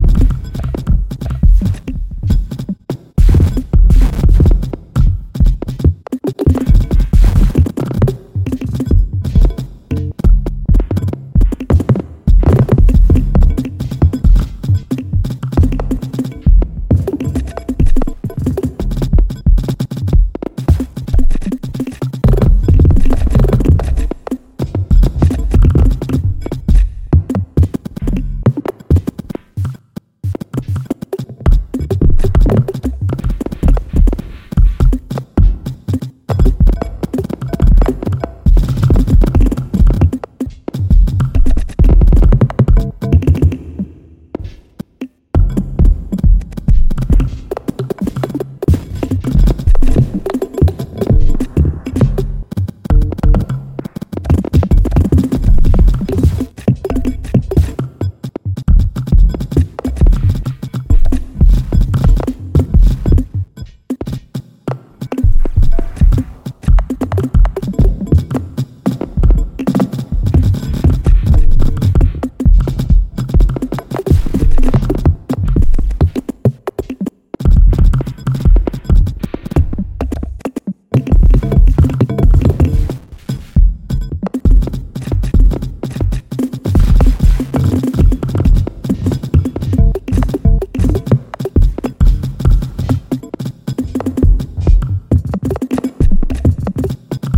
Many beats, all at once. Made in Tidalcycles